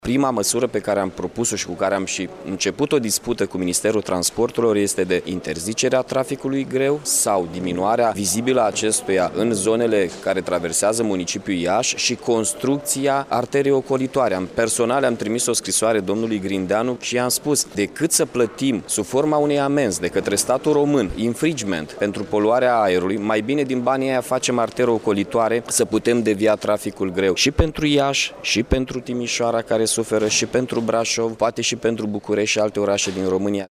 Mihai Chirica a mai spus că singura soluţie o reprezintă construirea şoselelor de centură şi interzicerea traficului greu în municipiu motiv pentru care la guvern s-a depus o solicitare de a fi alocate fonduri în regim de urgenţă în acest scop: